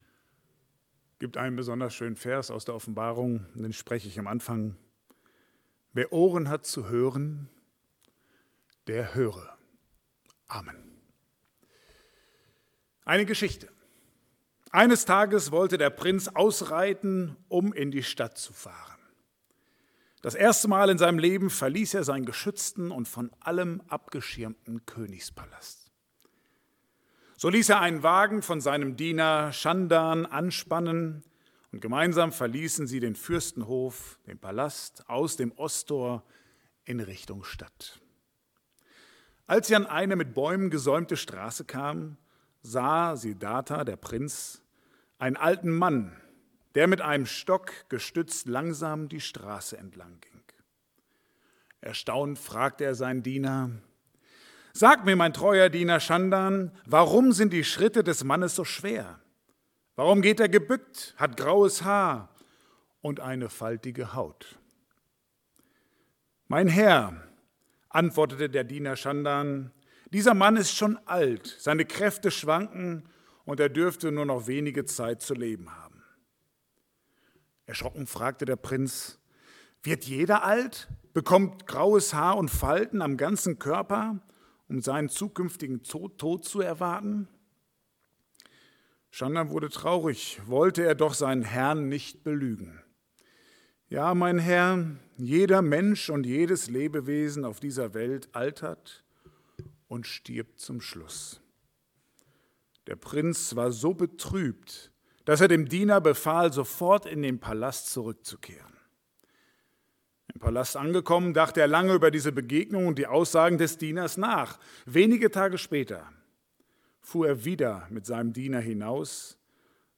Lukas 7,11-17 Dienstart: Gottesdienst « Es ist nichts mehr wie‘s früher war 9 Gedanken zu einem Wunder